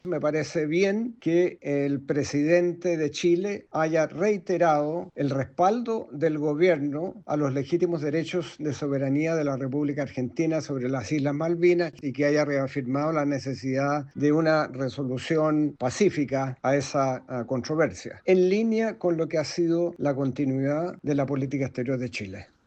Así lo planteó el excanciller Heraldo Muñoz, quien planteó que “me parece bien que el presidente de Chile haya reiterado el respaldo del Gobierno a los legítimos derechos de soberanía de la República Argentina sobre las islas Malvinas”.